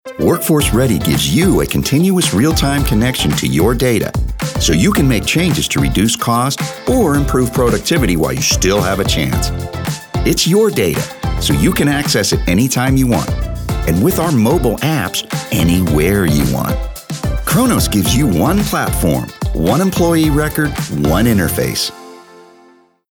anti-announcer, authoritative, confident, friendly, informative, middle-age, promo, upbeat